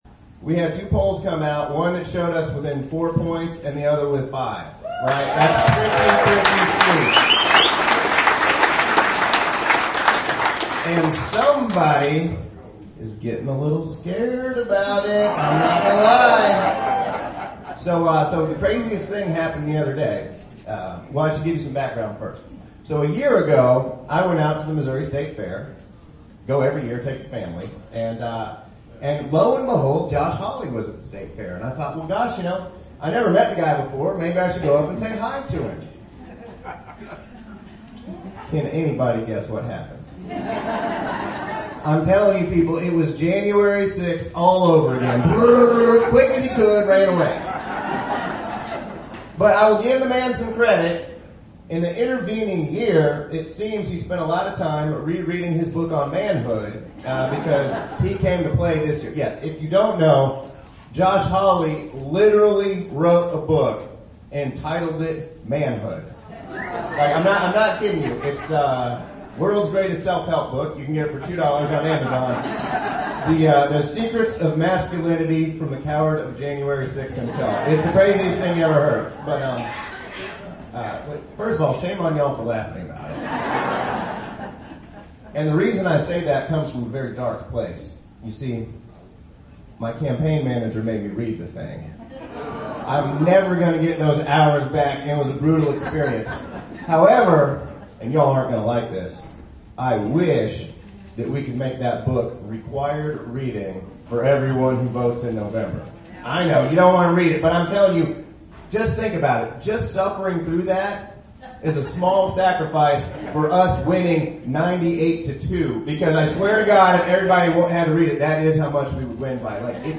Approximately 200 individuals from the area and neighboring counties attended.
rally